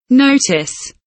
notice kelimesinin anlamı, resimli anlatımı ve sesli okunuşu